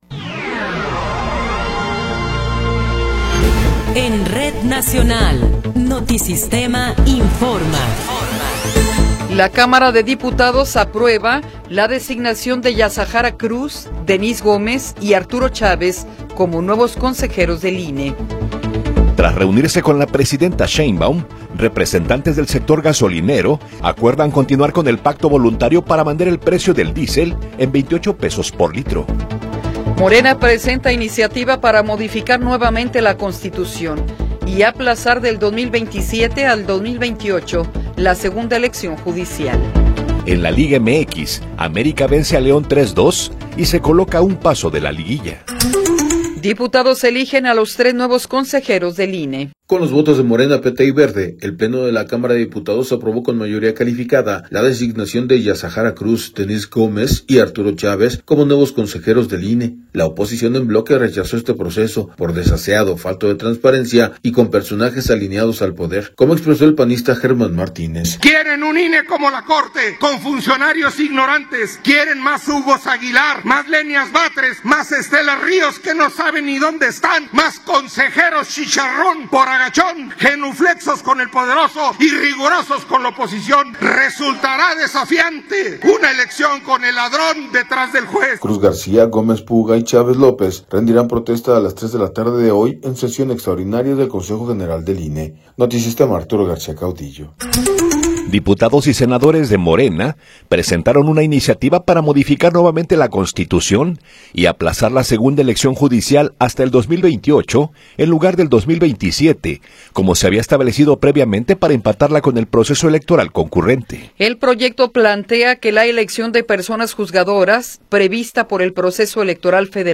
Noticiero 8 hrs. – 22 de Abril de 2026
Resumen informativo Notisistema, la mejor y más completa información cada hora en la hora.